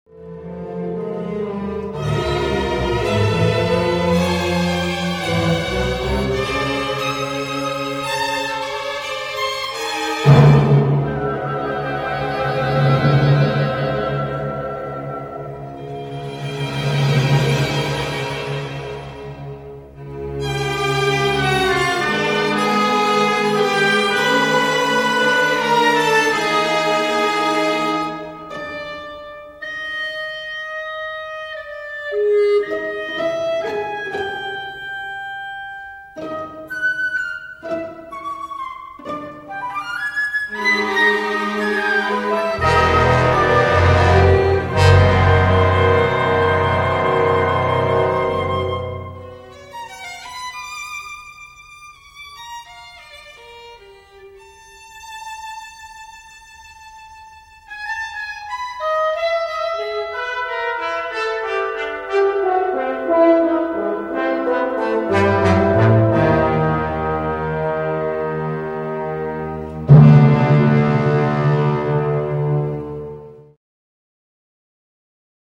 Cantata for:
Modern Symphony orchestra piece